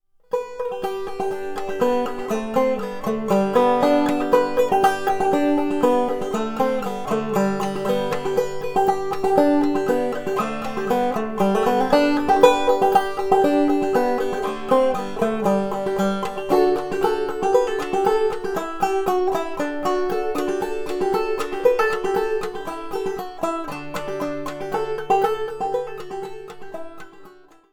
All recordings were done with the aid of a rim tester which allows me to record rims before they are made into a complete banjo.
VARIABLE: Thick Leading Edge
CONCLUSION: The rim with the thicker leading edge.had slightly more pronounced mid tones and a somewhat richer tone overall, however the rim with the tinner leading edge seemed a littlle more balanced.
The recordings exaggerate the effect..